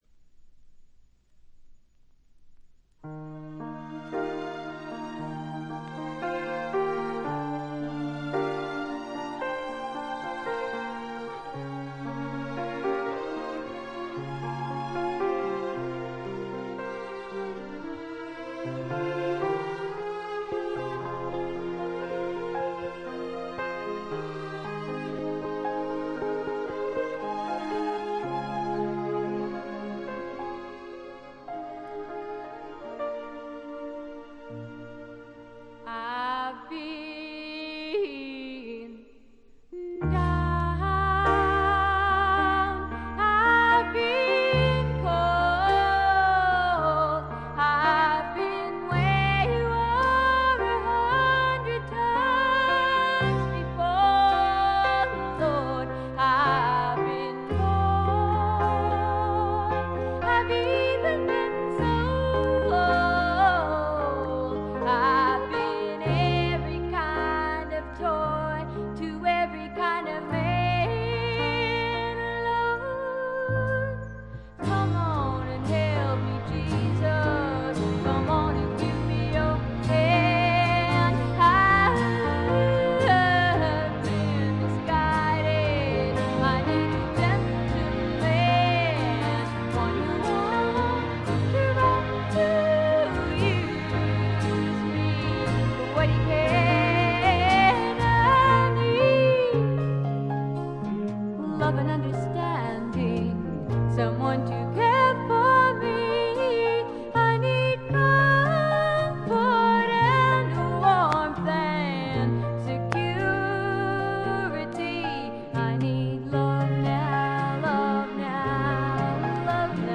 B5序盤と中盤で2連と3連のプツ音。
試聴曲は現品からの取り込み音源です。